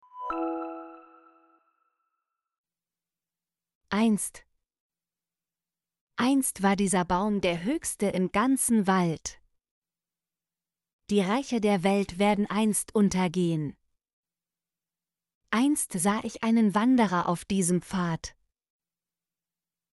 einst - Example Sentences & Pronunciation, German Frequency List